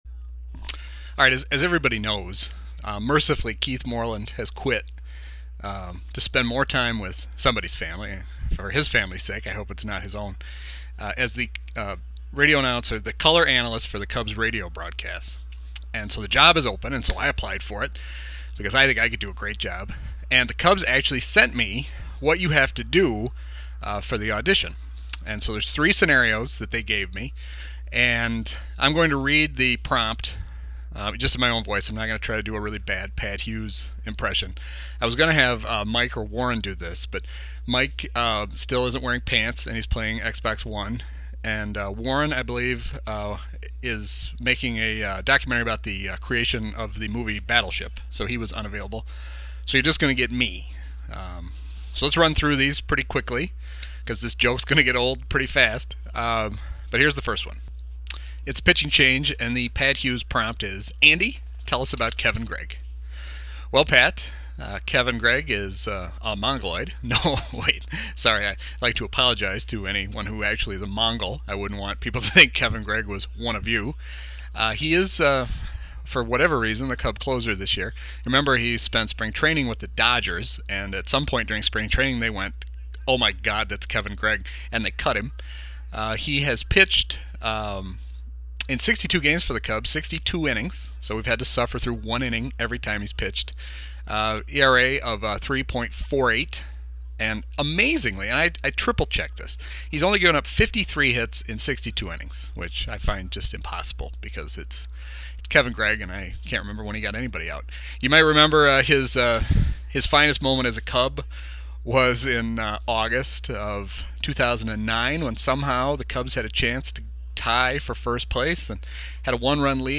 The winning Cubs radio analyst audition tape
The way it worked was they sent me three scenarios to respond to, all prompts from my new broadcast partner and best friend Pat Hughes.
wgnradio-cubs-audition-final.mp3